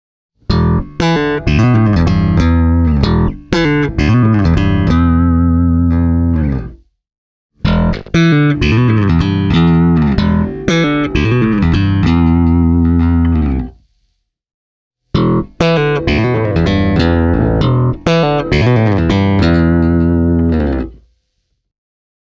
I also enjoyed the Classic’s clear, yet warm high-mid presence, which keeps the sound nicely organic.
I recorded the audio clips via a Focusrite Saffirer 6 USB -soundcard straight into the audiosequencer, without any kind of amplifier modelling. Each clip starts with the neck pickup:
Spector Legend 4 Classic – slap